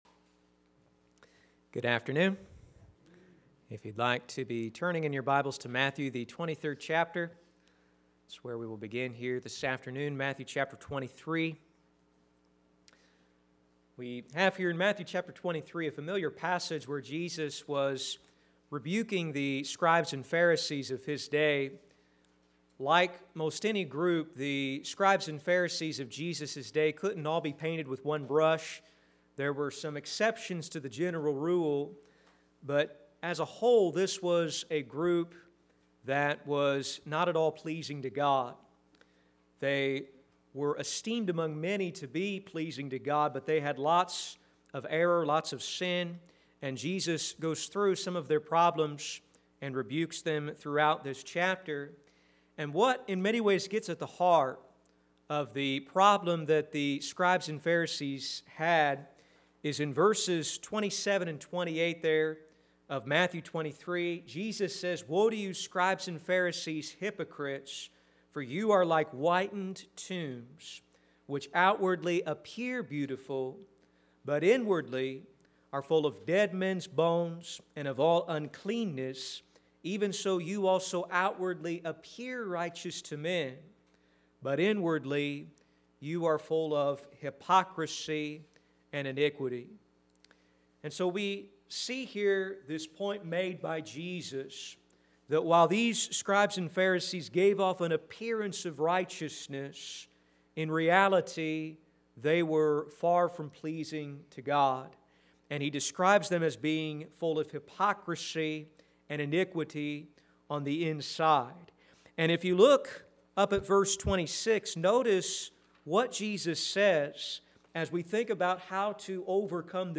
Sermons - Olney Church of Christ
Gospel Meeting – Summer 2021